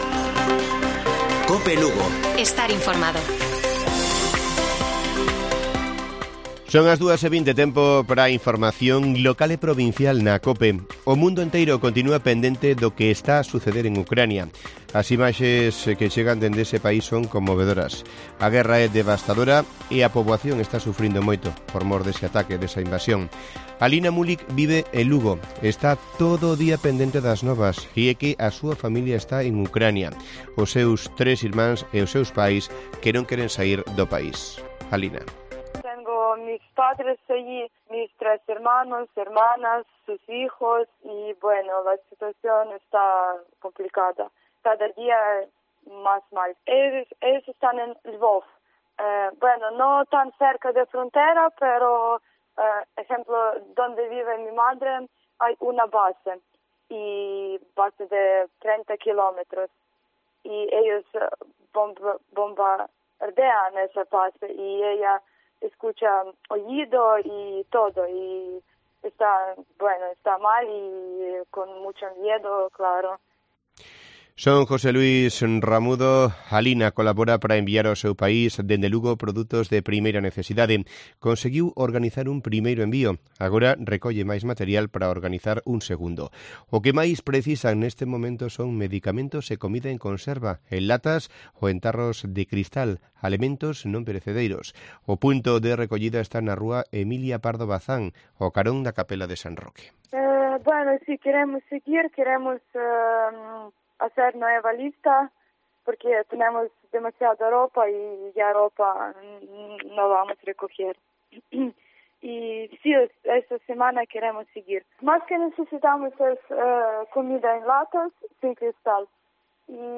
Informativo Mediodía de Cope Lugo. 07 de marzo. 14:20 horas